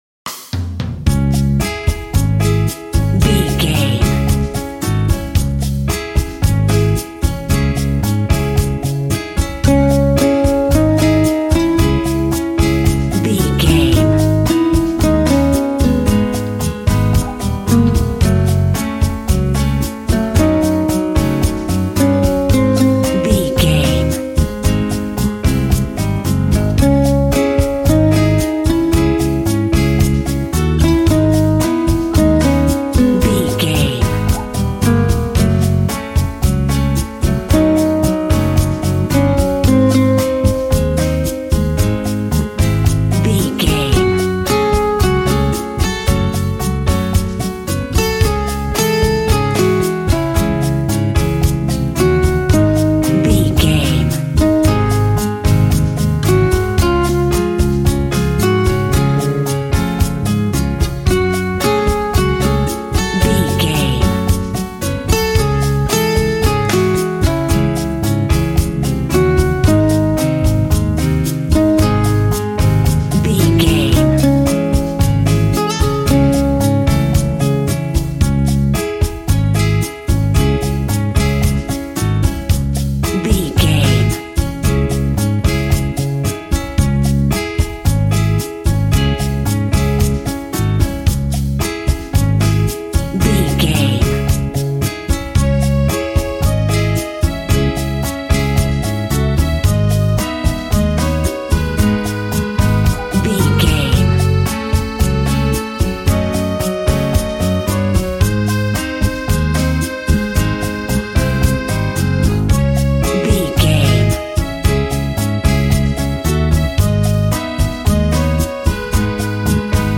Aeolian/Minor
D♭
funky
energetic
romantic
percussion
electric guitar
acoustic guitar